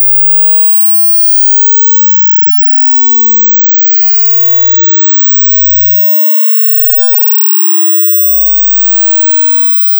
AudioCheck Tone Tests
High Frequency Tones
TEST NOTICE: Many high frequency tones are not audible to people approximately over the age of 25.